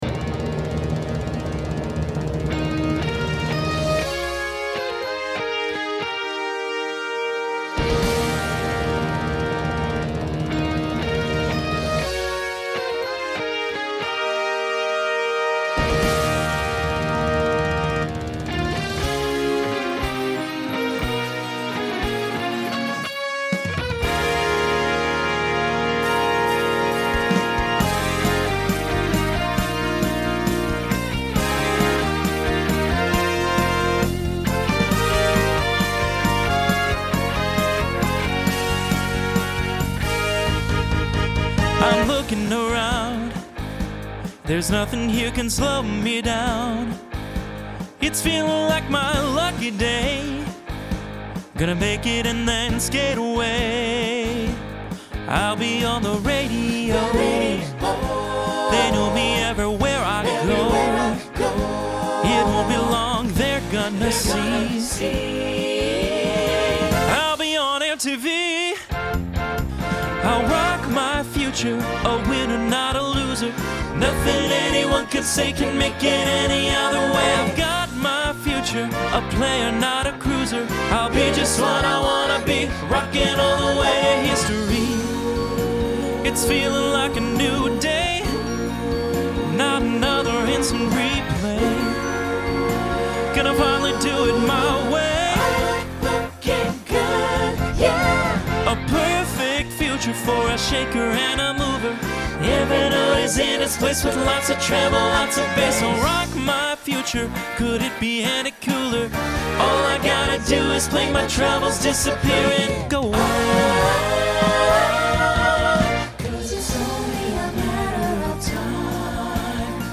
Solo Feature Voicing SATB